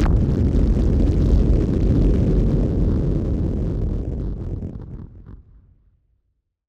BF_SynthBomb_C-04.wav